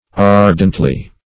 Ardently \Ar"dent*ly\, adv.